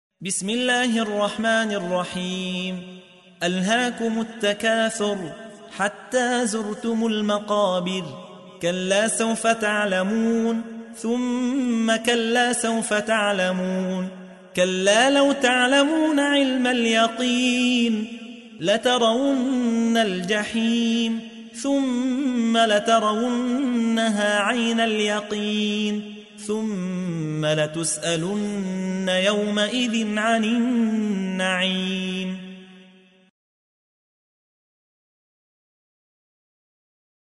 تحميل : 102. سورة التكاثر / القارئ يحيى حوا / القرآن الكريم / موقع يا حسين